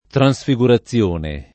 vai all'elenco alfabetico delle voci ingrandisci il carattere 100% rimpicciolisci il carattere stampa invia tramite posta elettronica codividi su Facebook transfigurazione [ tran S fi g ura ZZL1 ne ] → trasfigurazione